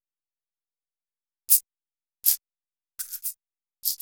MARRRACASS-L.wav